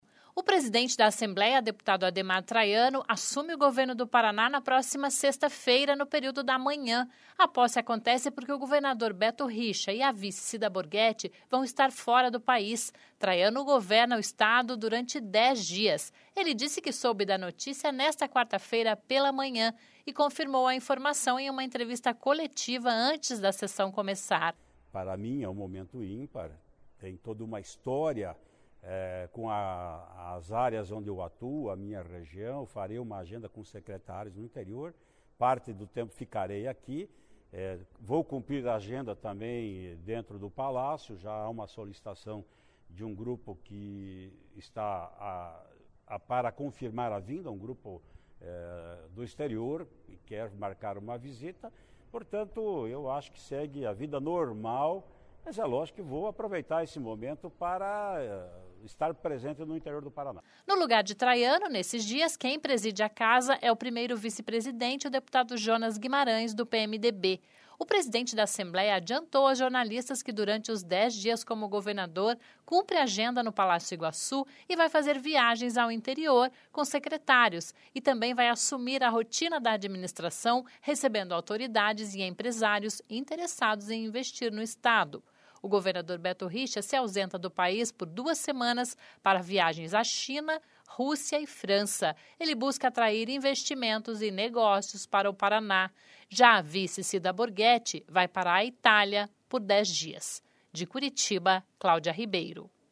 Ele disse que soube da notícia nesta quarta-feira (7) e confirmou a informação em uma entrevista coletiva antes da sessão começar.
(Sonora)